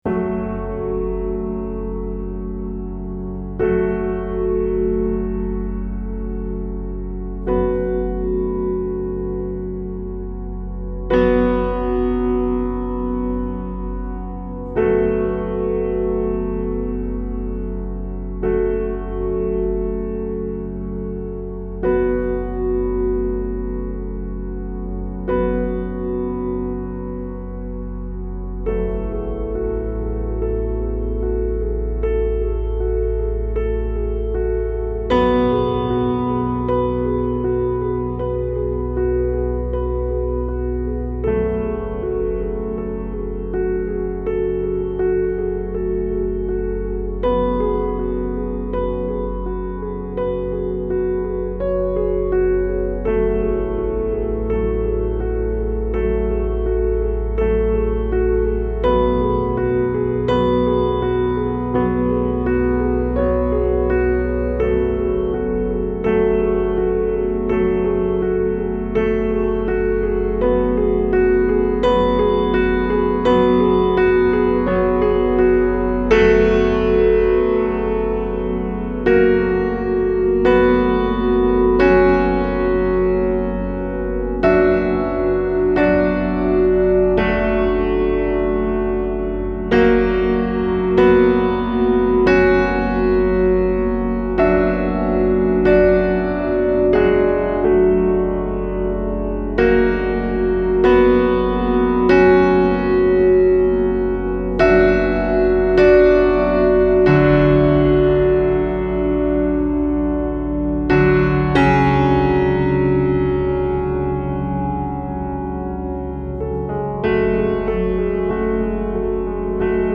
Style Style Soundtrack
Mood Mood Dark, Intense, Sad
Featured Featured Organ, Piano